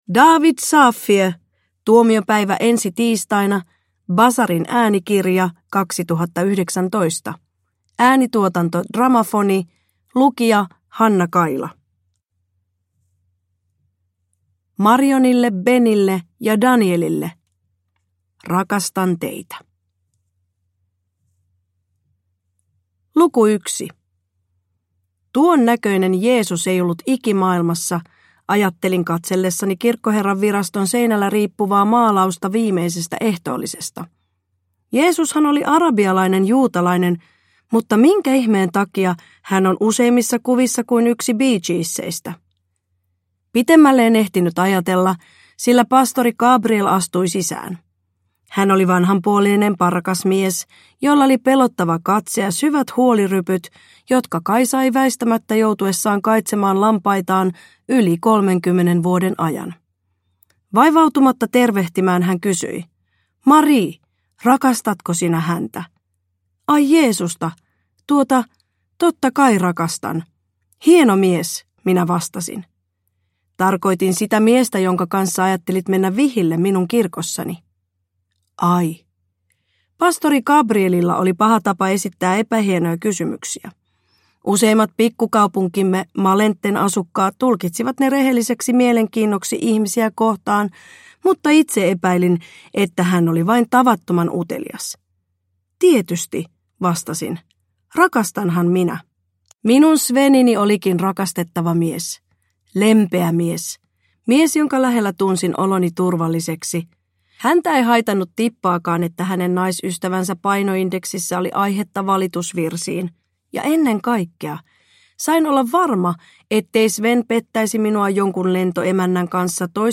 Tuomiopäivä ensi tiistaina – Ljudbok – Laddas ner